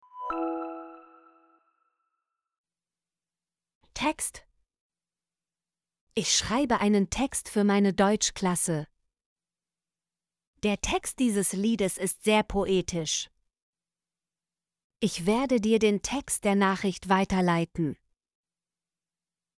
text - Example Sentences & Pronunciation, German Frequency List